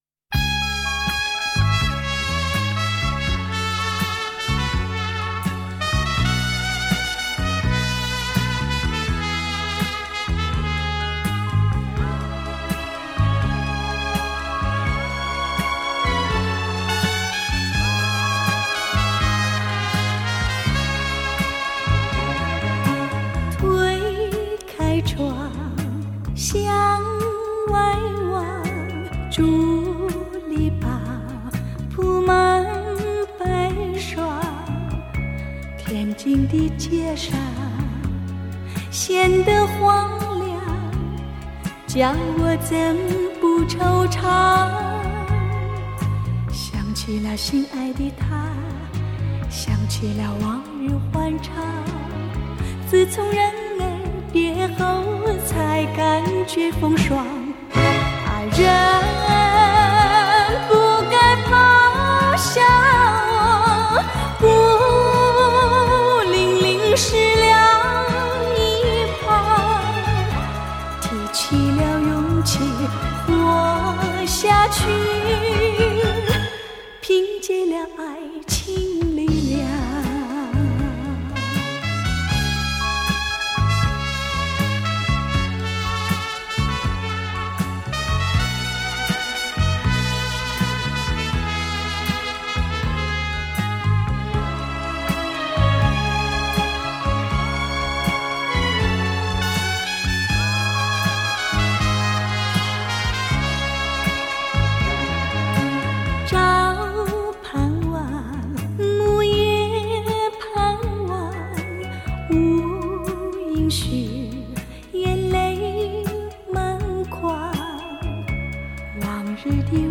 香港DSD版